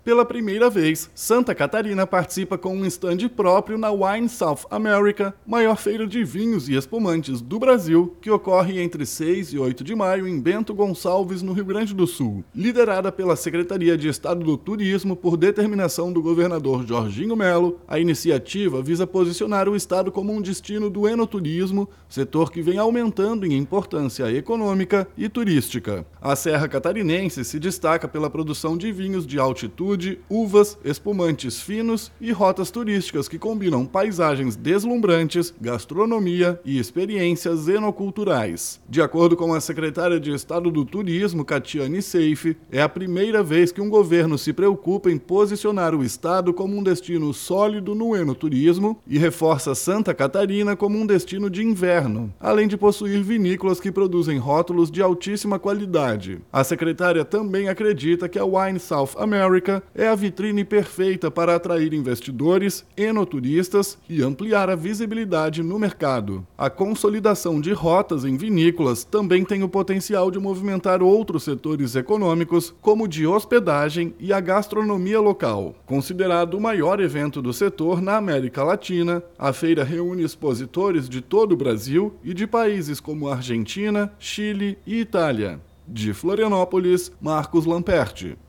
BOLETIM – Santa Catarina estreia na maior feira de vinhos do país para consolidar o enoturismo como vocação estratégica